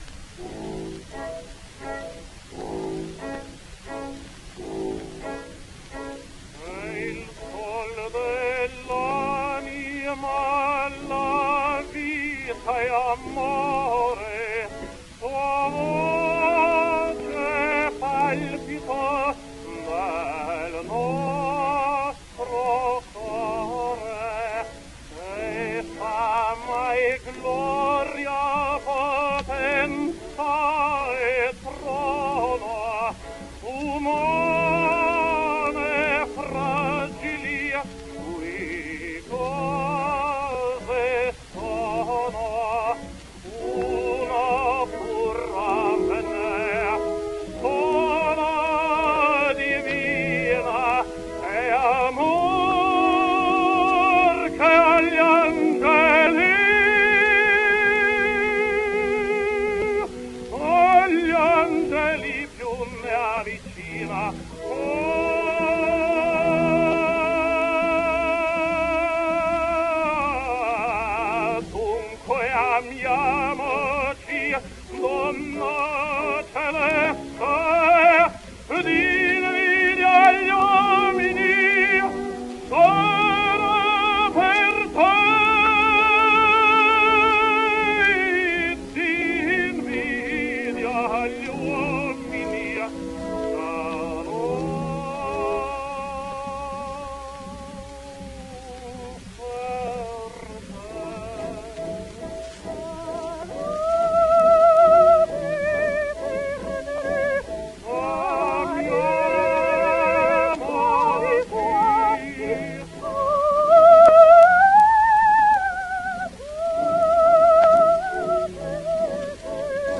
tenore